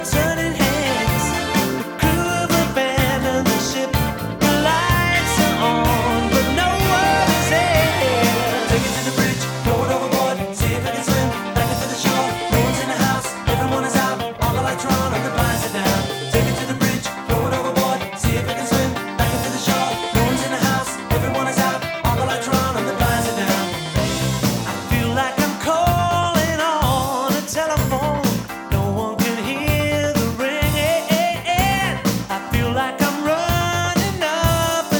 Жанр: Поп / Рок / Альтернатива